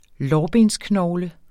Udtale [ ˈlɒːˌbens- ]